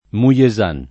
[ mu L e @# n ]